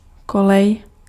Ääntäminen
US : IPA : [ɹeɪl]